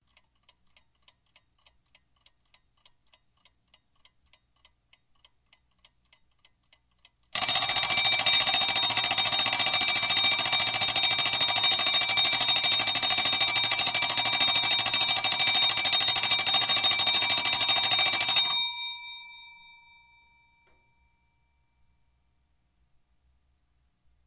时钟滴答声
描述：时钟滴答声。
标签： 10 滴答 时钟
声道立体声